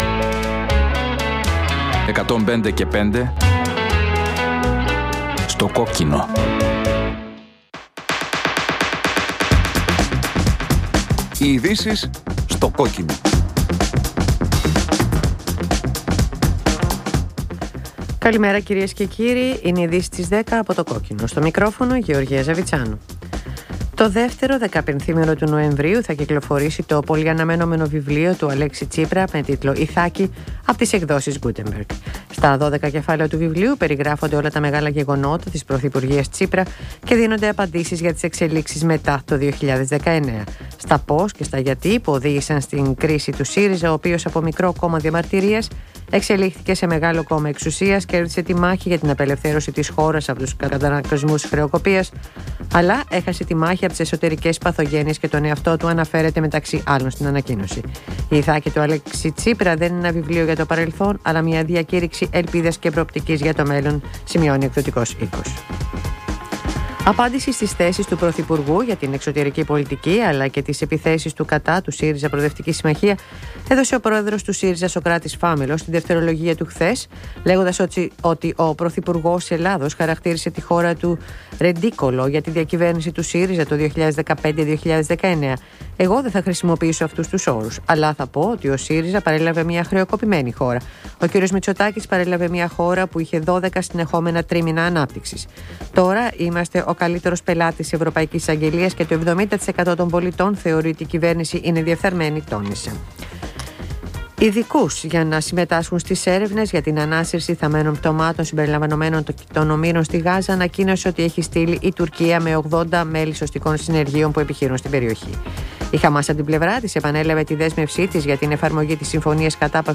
Συνέντευξη της Ρένας Δούρου Στο Κόκκινο